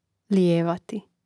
lijévati lijevati